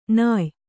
♪ nāy